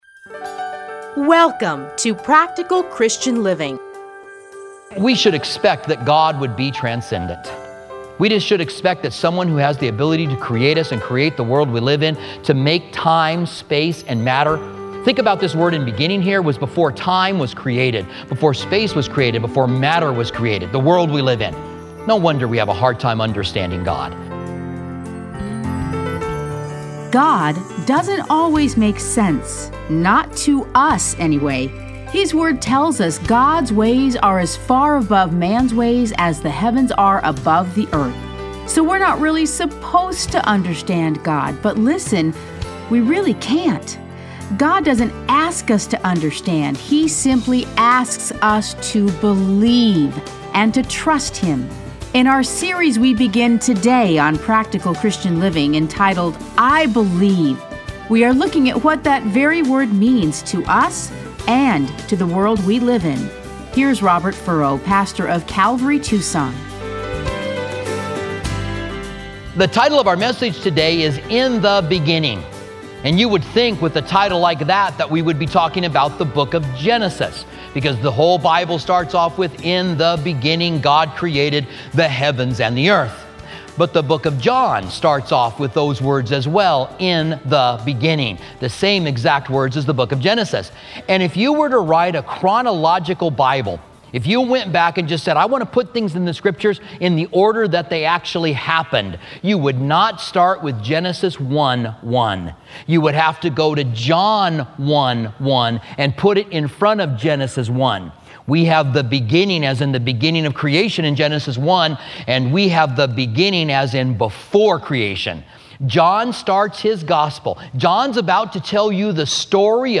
Listen to a teaching from John 1:1-18.